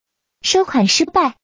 pay_fail.wav